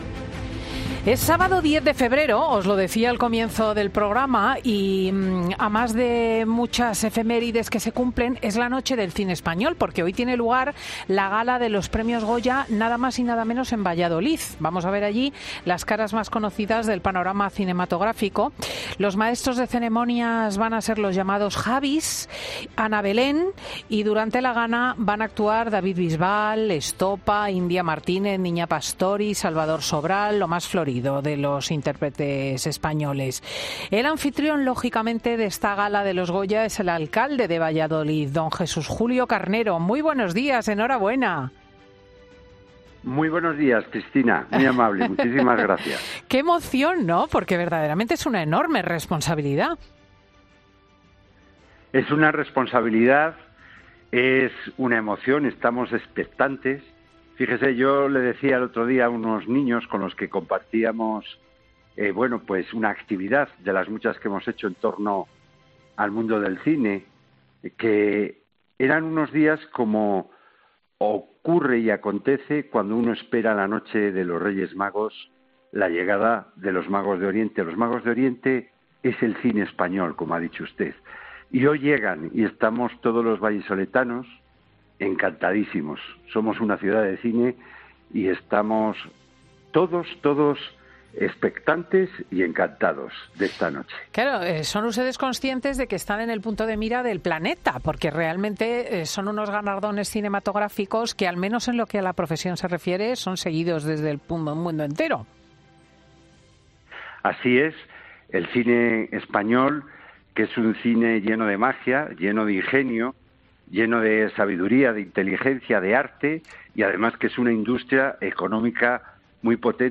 El alcalde de Valladolid, Jesús Julio Carnero, pasa por los micrófonos de 'Fin de Semana' para contarnos cómo se prepara la ciudad ante un evento de tal envergadura